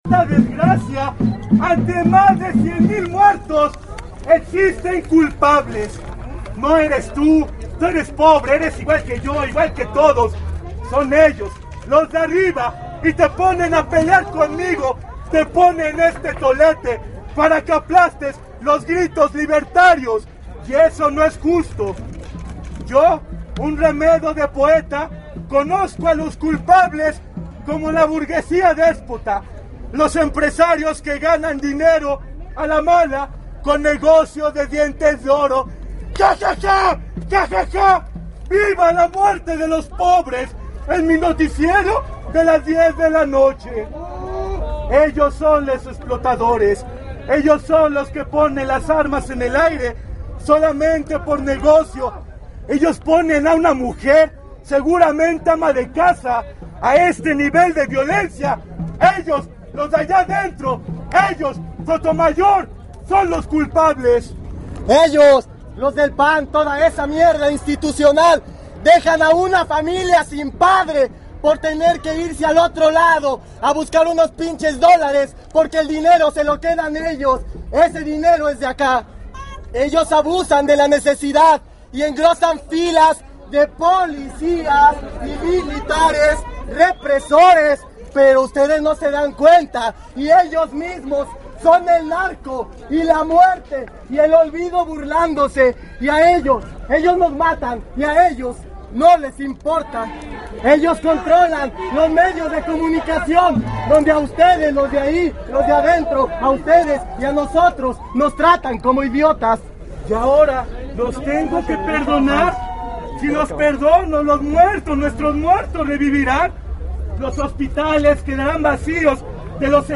La primera parada de hizo en la sede nacional del PAN, en donde los granaderos asumieron un papel de protección de intereses, hicieron una barrera, la cual no impidió que un par de poetas comenzaran a recitar frente a los policías, quienes optaron por burlarse o voltear la mirada hacia otros lugares.
Poesia.mp3